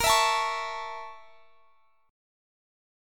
BbmM7b5 Chord
Listen to BbmM7b5 strummed